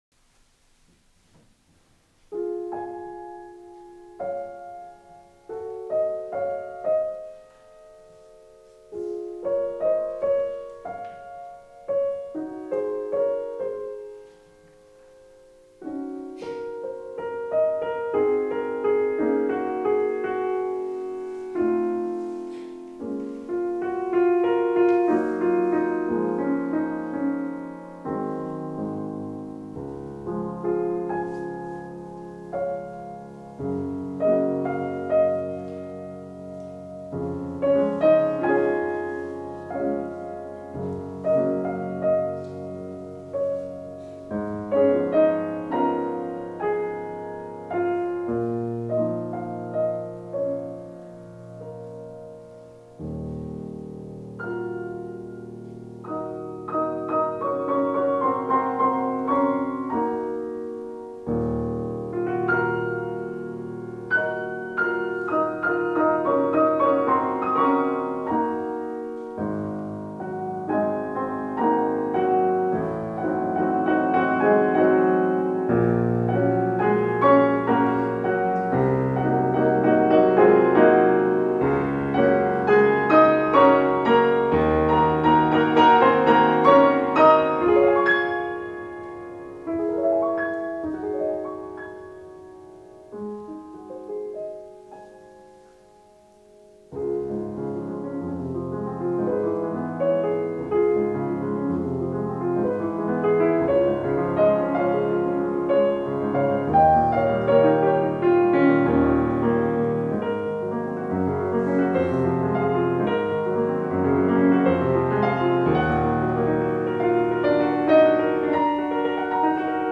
ちなみに、8年前2016年のリサイタルの時の演奏はコチラ
もとの演奏ももちろんすばらしいのですが、今回と比べると音が硬質な感じがしました。